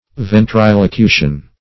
Ventrilocution \Ven`tri*lo*cu"tion\